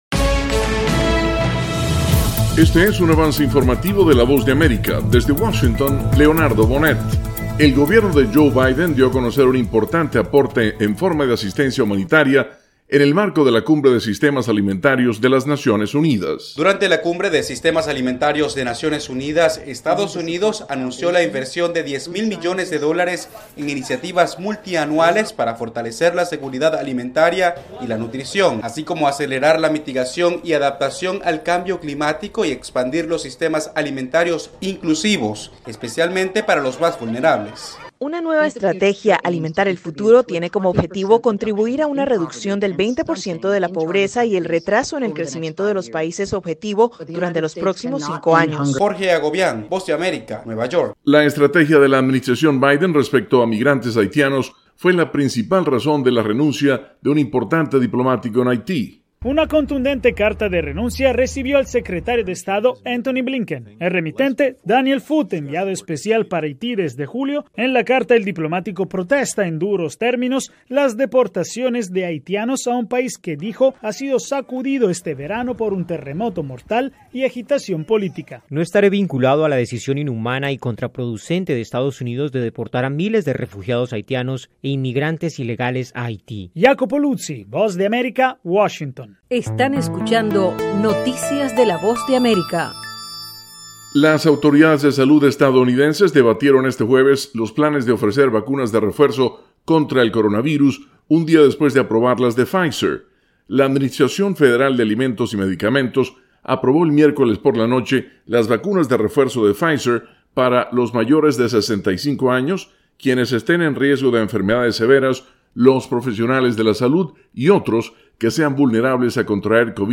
Resumen informativo con noticias de Estados Unidos y el resto del mundo